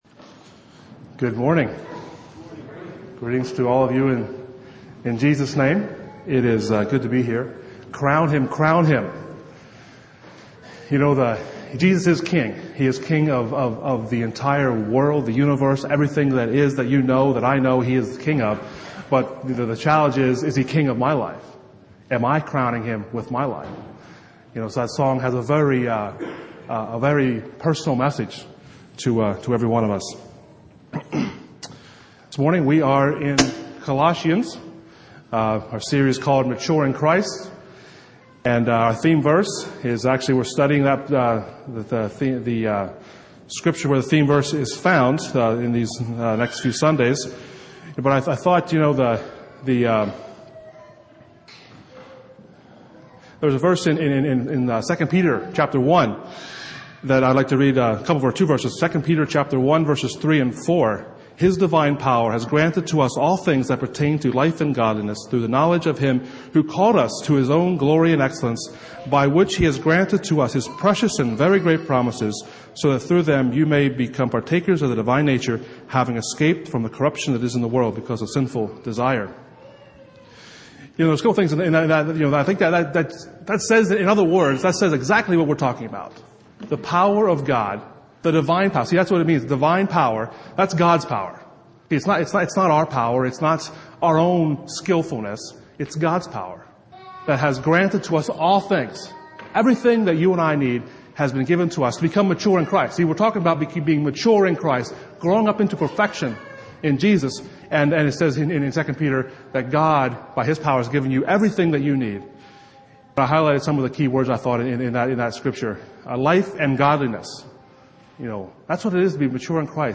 Service Type: Saturday Morning